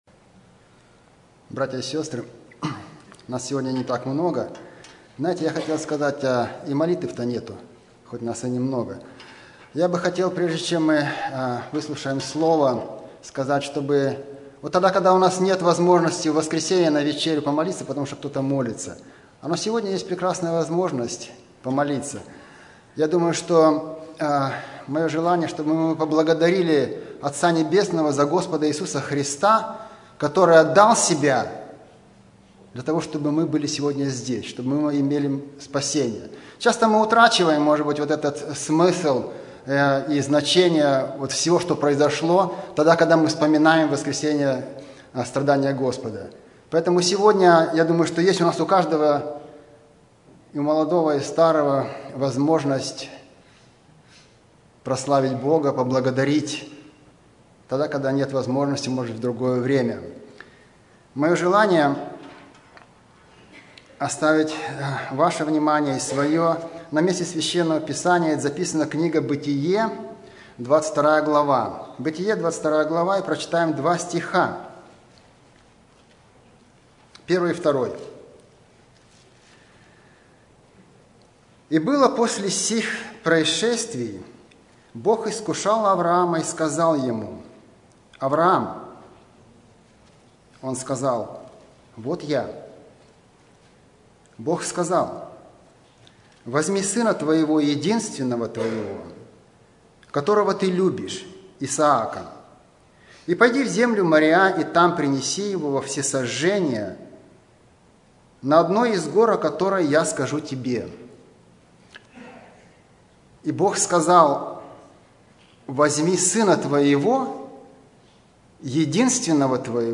Все Проповеди